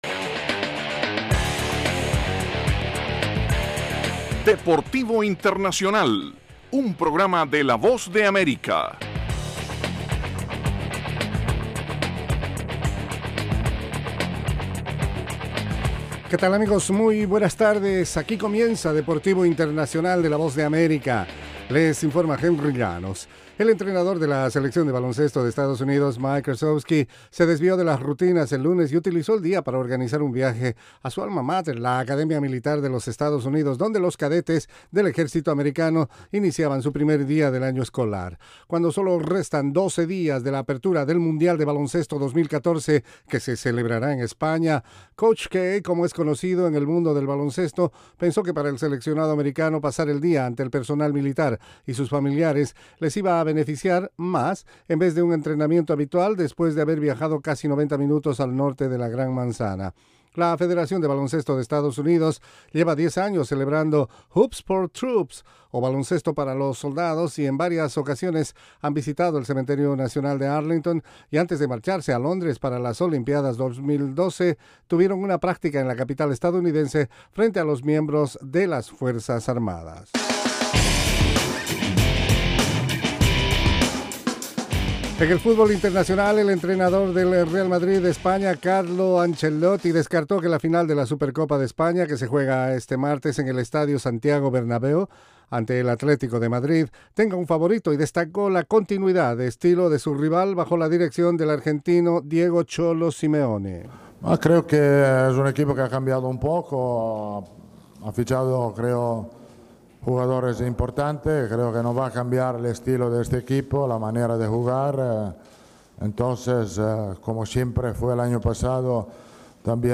desde los estudios de la Voz de América